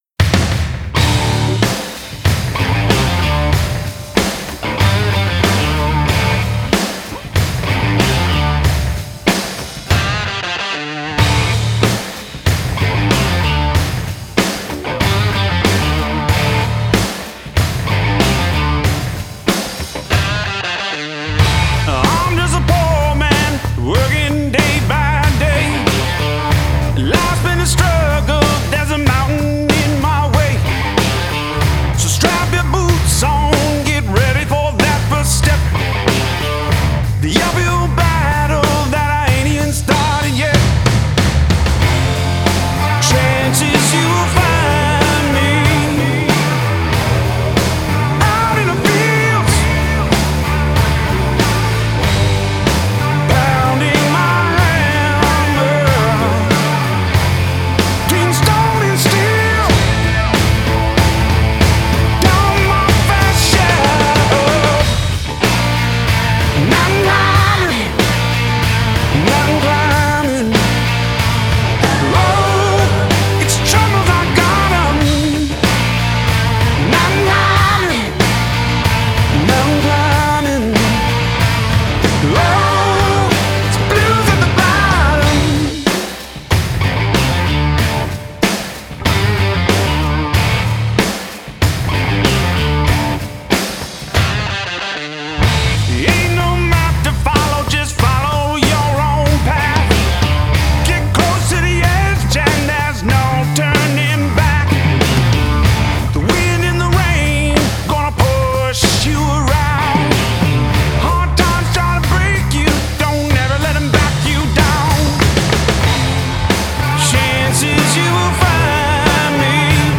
Genre: Blues Rock